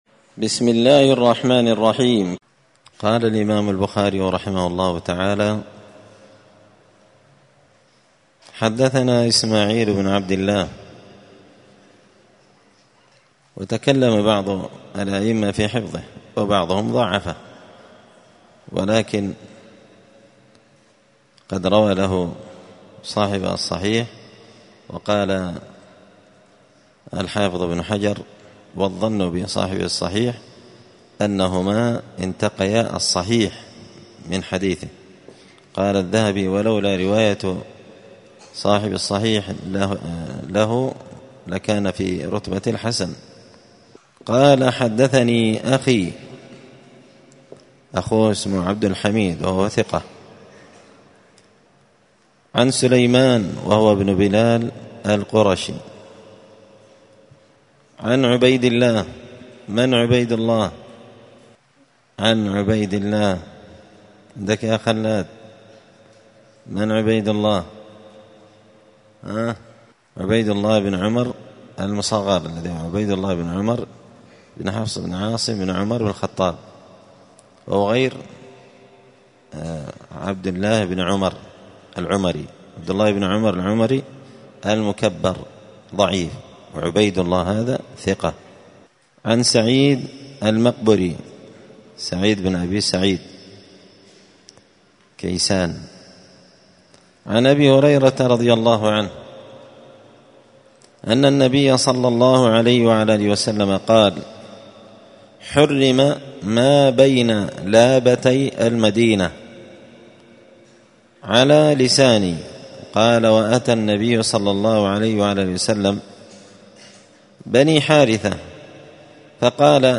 مسجد الفرقان قشن المهرة اليمن 📌الدروس اليومية